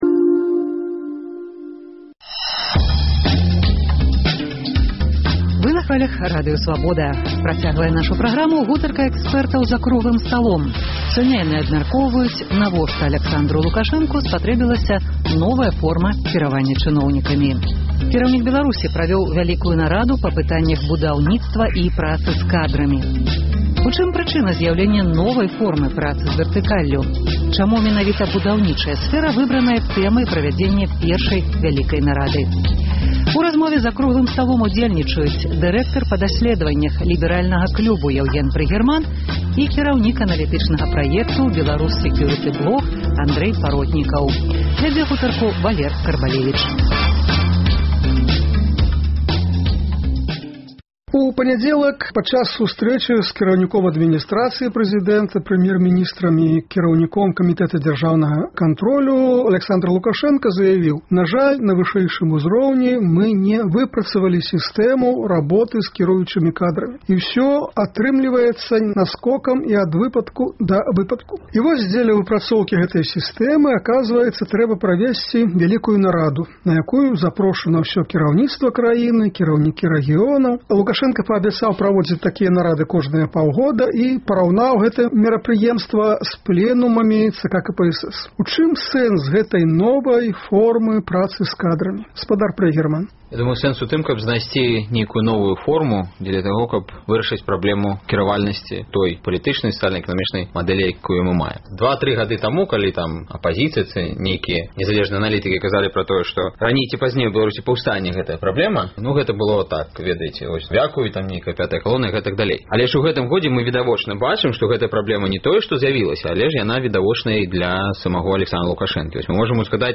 За круглым сталом «Экспэртызы «Свабоды»